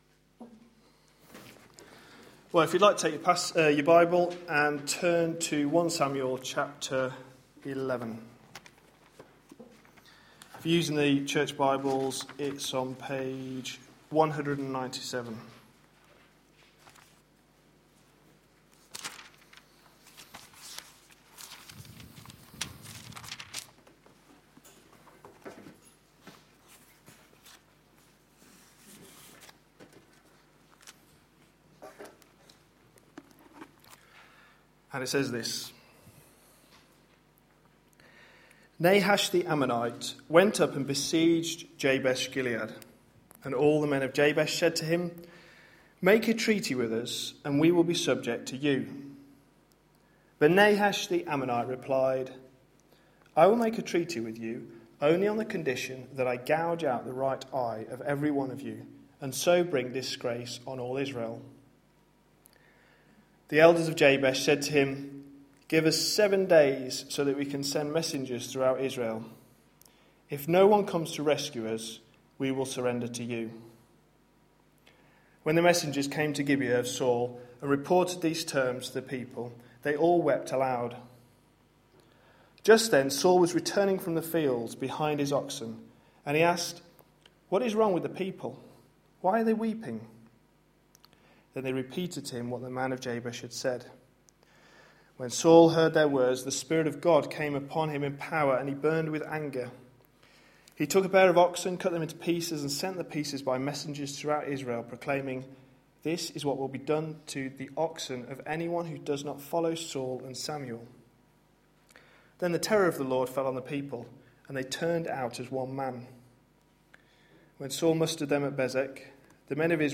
A sermon preached on 5th May, 2013, as part of our God's King? series.